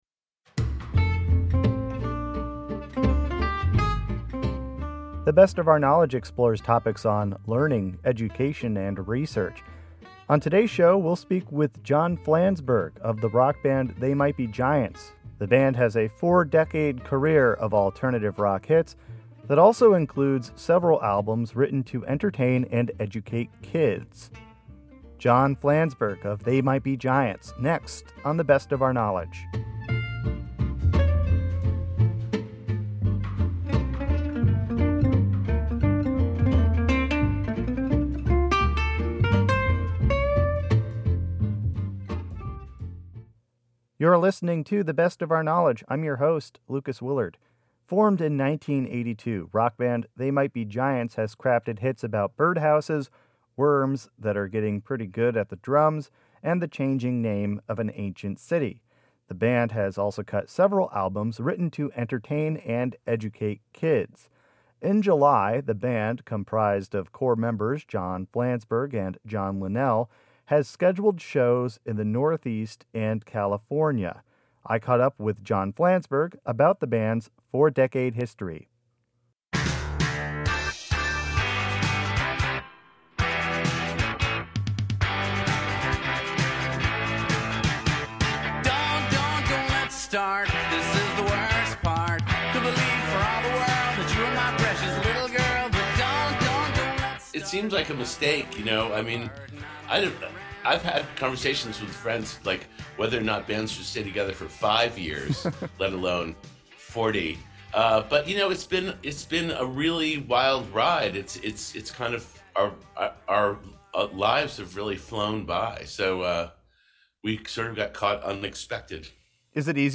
#1709: A Conversation with John Flansburgh of They Might Be Giants| The Best of Our Knowledge - WAMC Podcasts